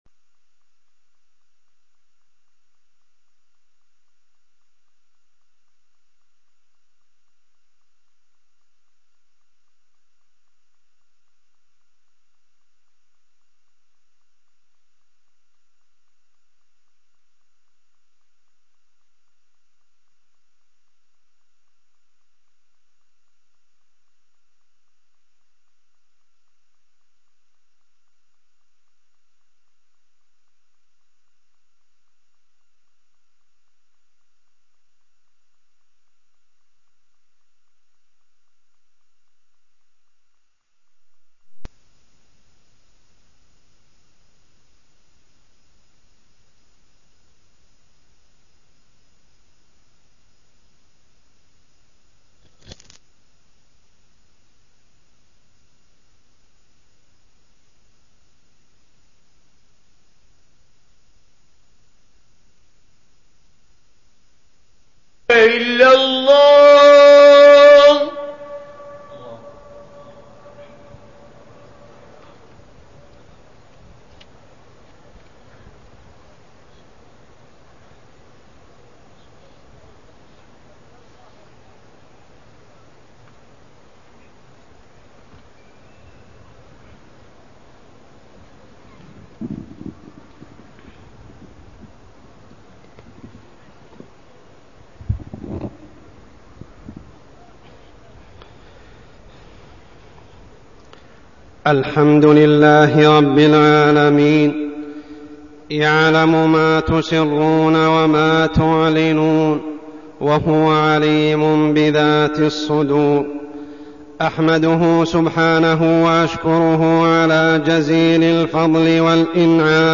تاريخ النشر ١٩ محرم ١٤١٩ هـ المكان: المسجد الحرام الشيخ: عمر السبيل عمر السبيل الإخلاص لله تعالى